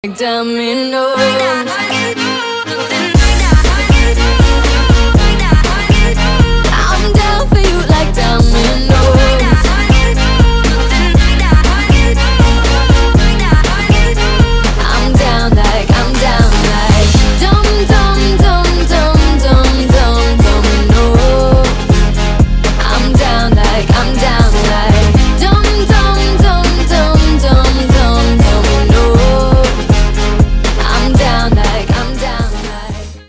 • Качество: 320, Stereo
женский вокал
dance
Electronic
Стиль: midtempo, future bass